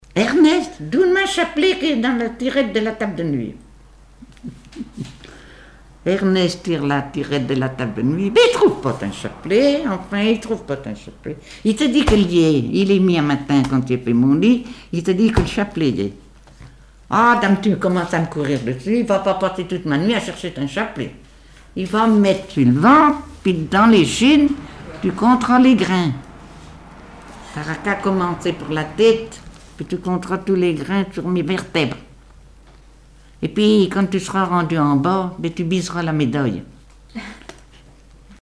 Genre sketch
Témoignages et chansons
Catégorie Récit